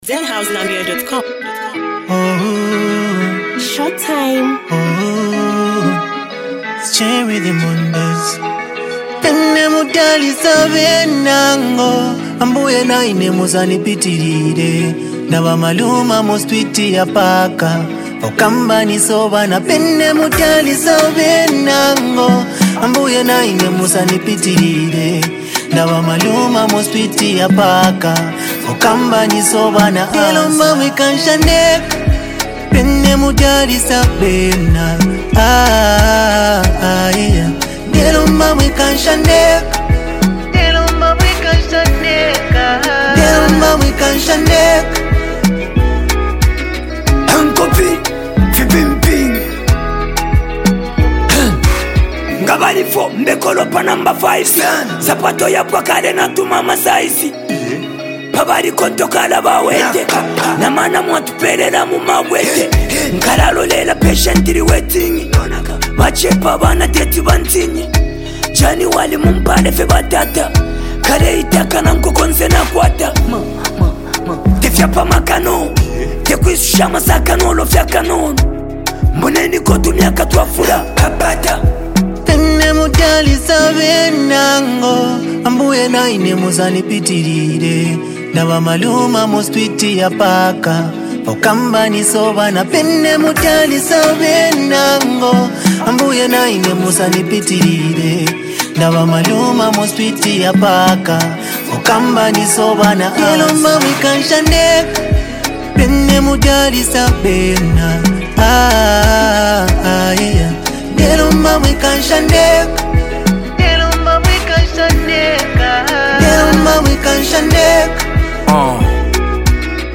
a soulful and emotional track that speaks on love